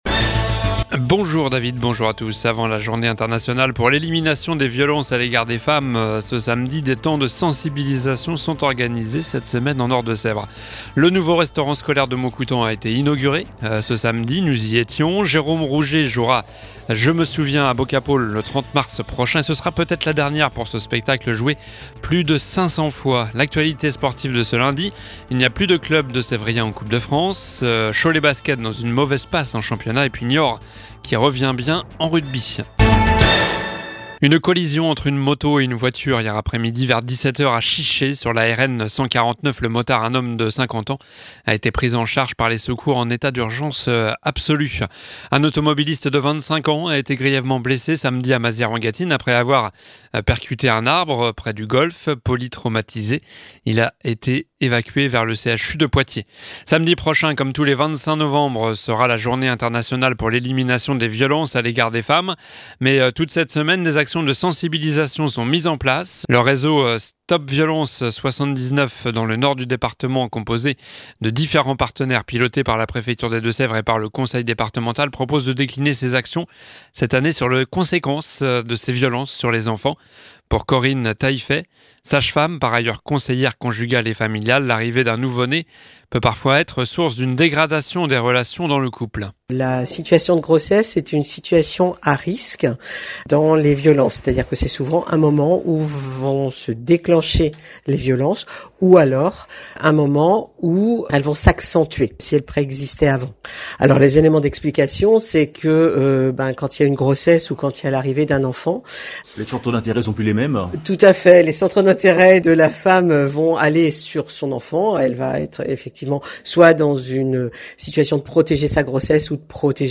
Journal du lundi 20 novembre (midi)